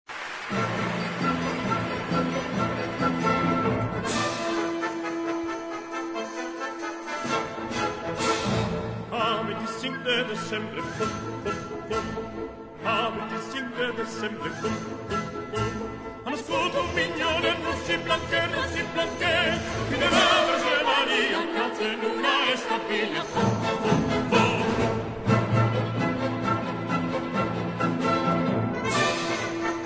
music: Traditional
key: D-major